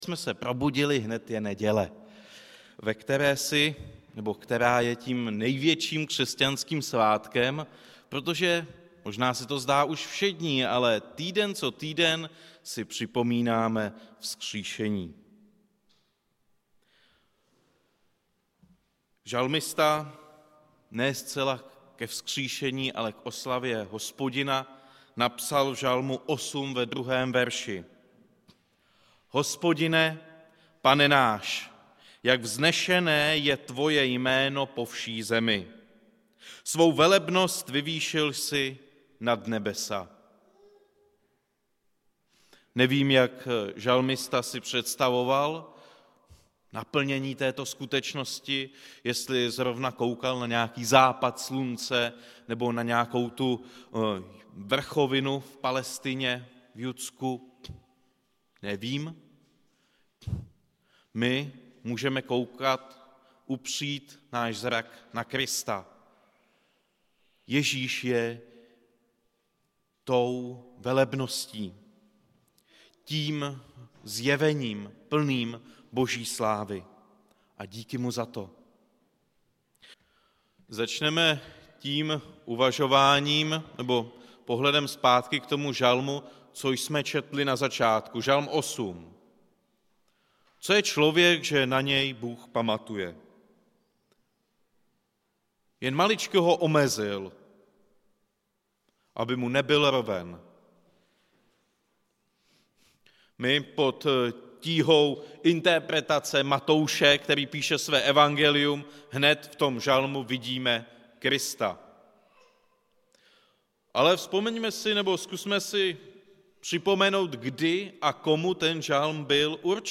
Událost: Kázání
Místo: Římská 43, Praha 2